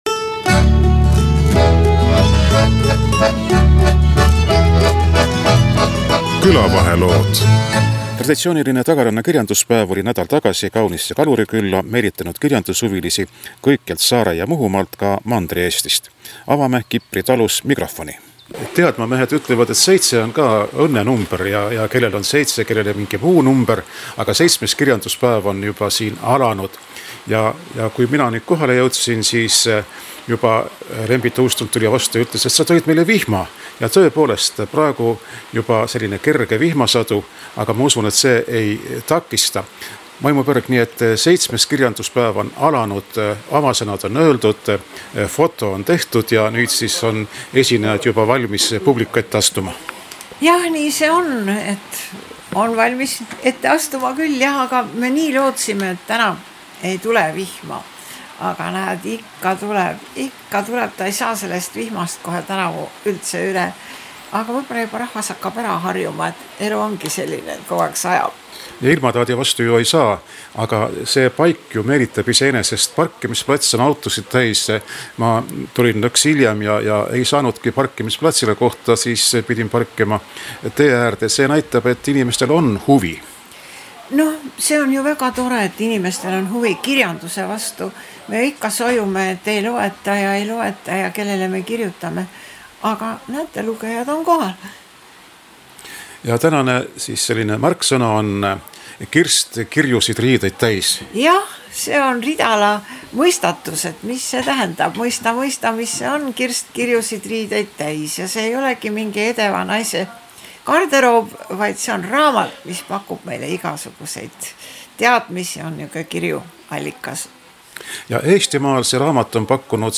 Saates saavad sõna kirjandushuvilsed saarlased, muhulased ja Soomest Tagarannale sõitnud inimesed. Kipri talu hoovis oli ka kuulmisbuss.